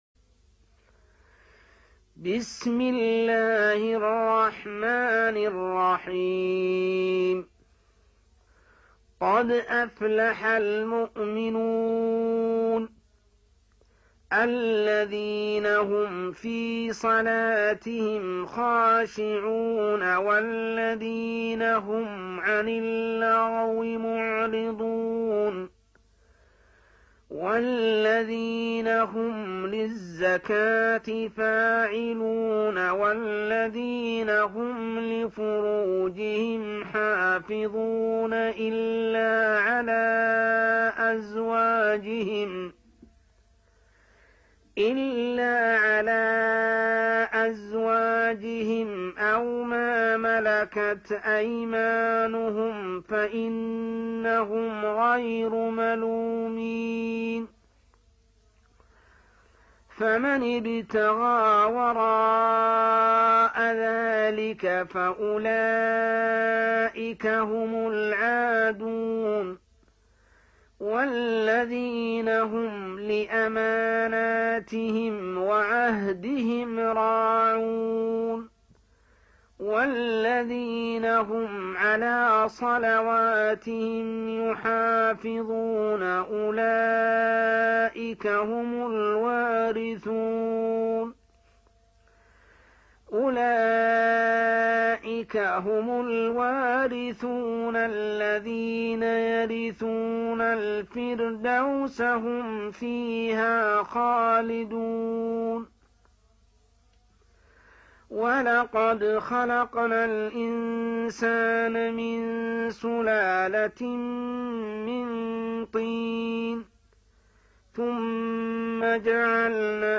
سورة المؤمنون | Surah Al-Mu'minun > المصحف المرتل للشيخ محمد السبيل > المصحف - تلاوات الحرمين